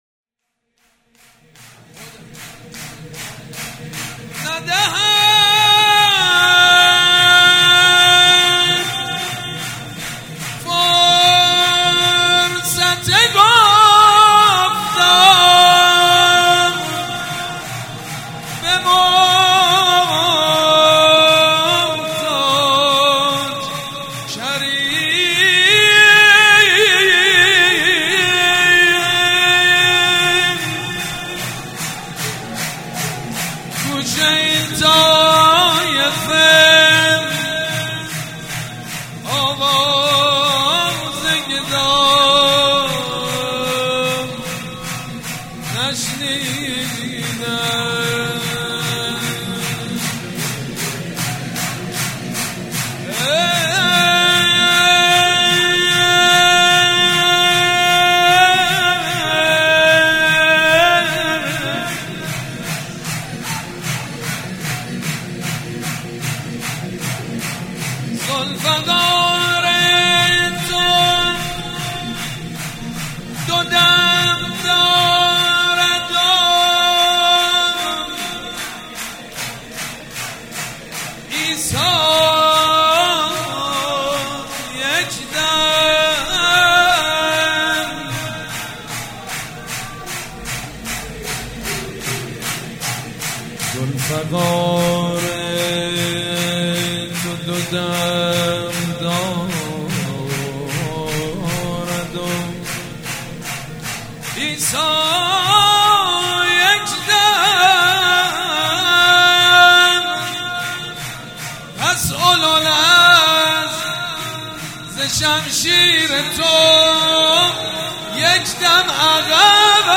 نغمه: ندهد فرصت گفتار به محتاج کریم